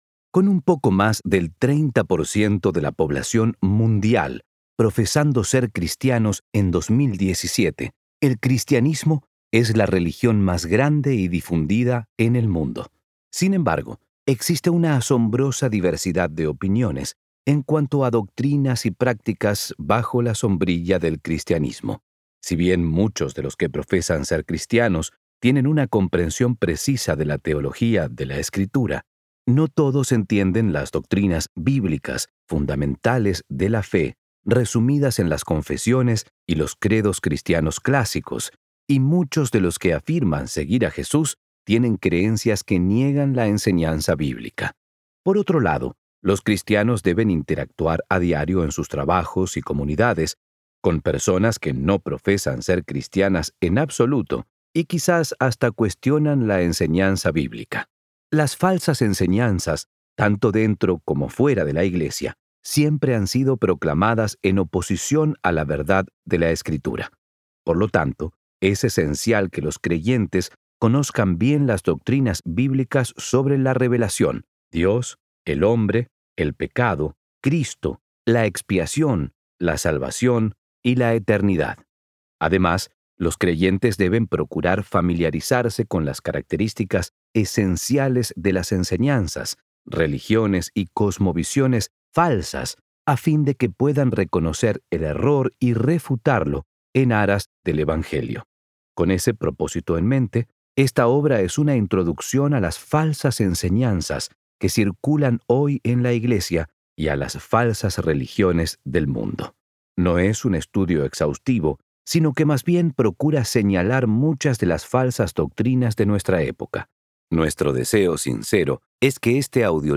A Field Guide on False Teaching: Audiobook Download (Spanish), Book | Ligonier Ministries Store